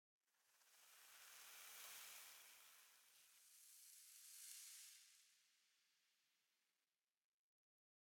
sounds / block / sand
sand6.ogg